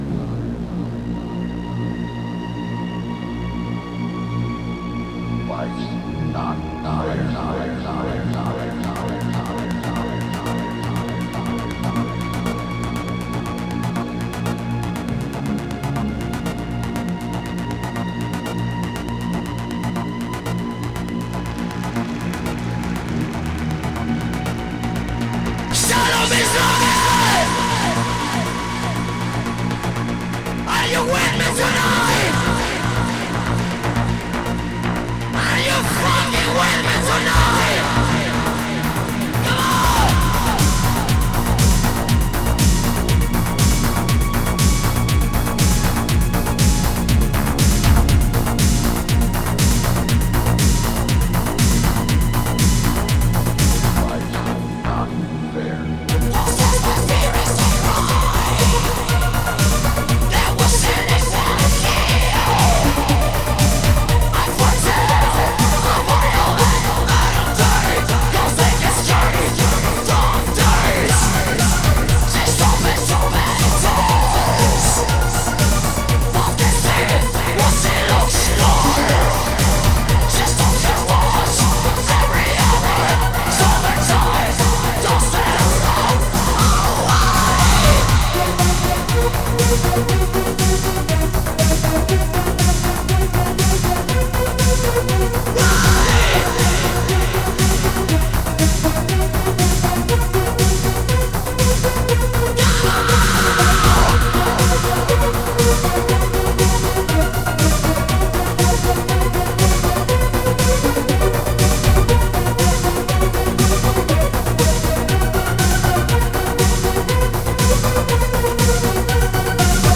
Электронная музыка